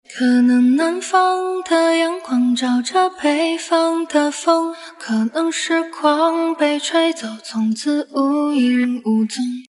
分离后的人声：